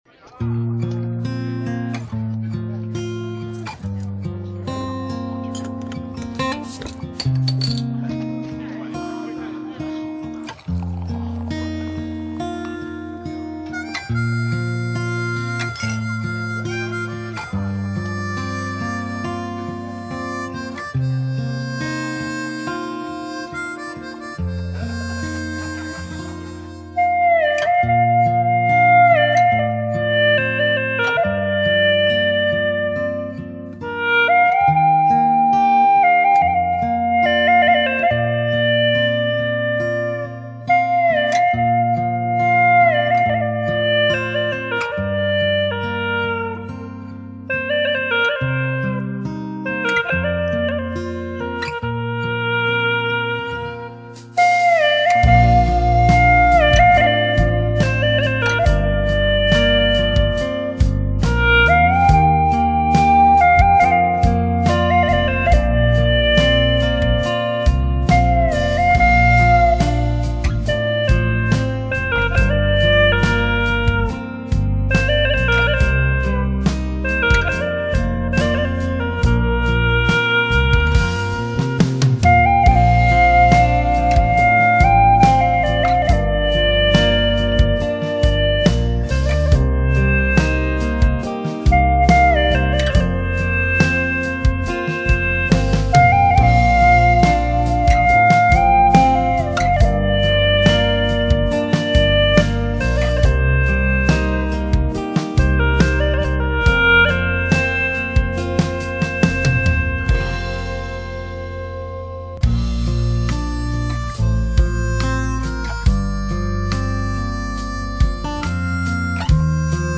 调式 : 降B 曲类 : 流行